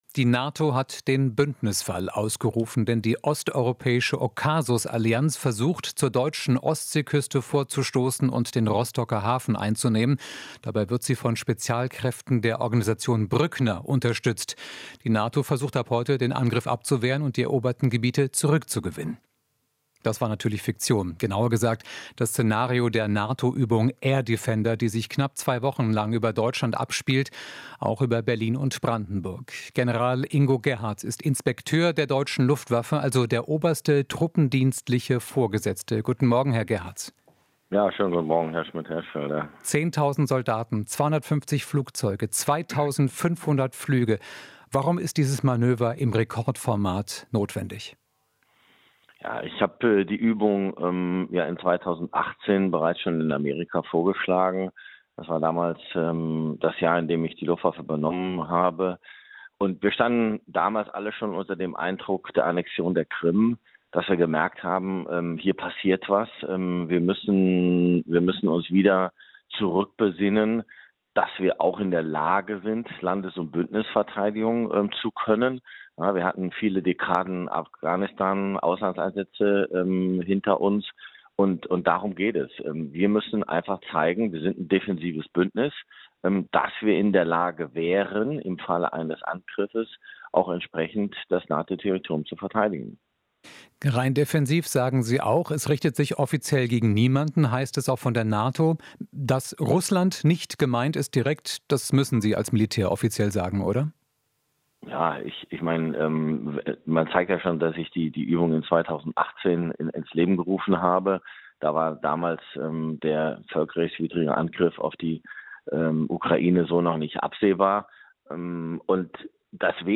Interview - Luftwaffen-Inspekteur: Übung ist klares Signal der Stärke